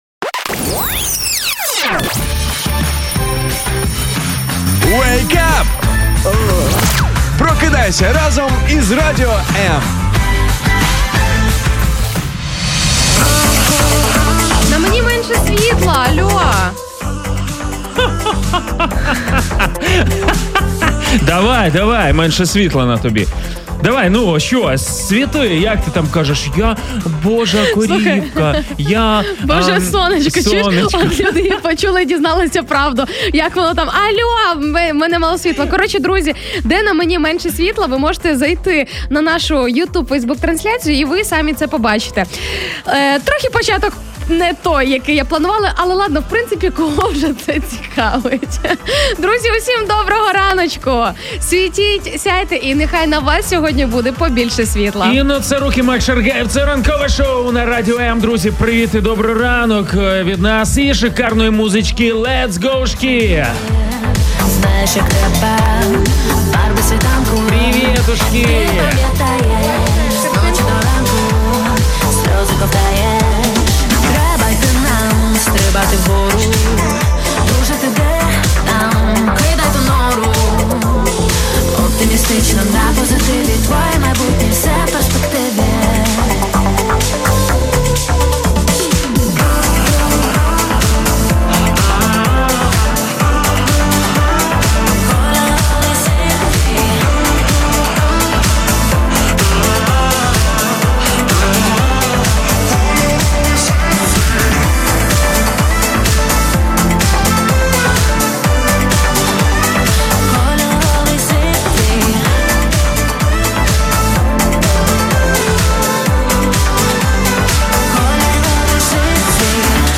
Чи щасливі ви на вашій роботі? 21/10/2021 Робота бо треба чи робота по серцю? Про це говоримо в ранковому шоу Як виходити з глухих кутів? 20/10/2021 Як виходити з глухих кутів?